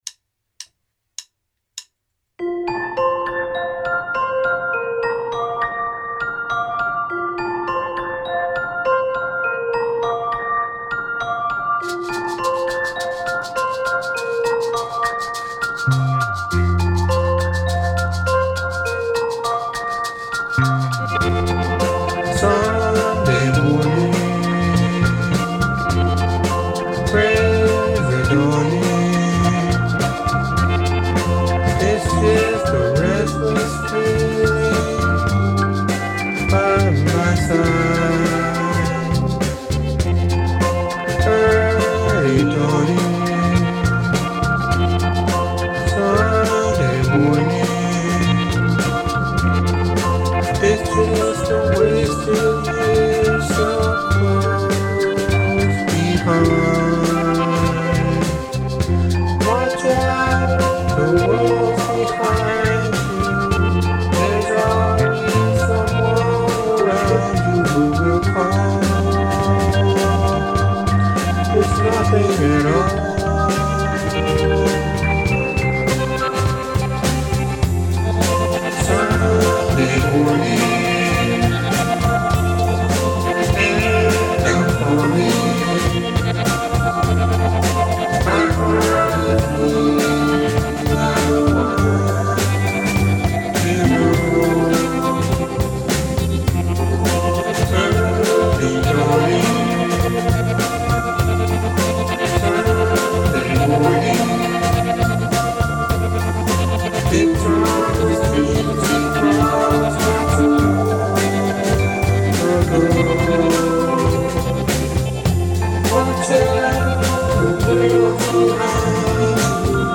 旦那さん、Steel Guitarを引っ張りだして、夜中にrecording。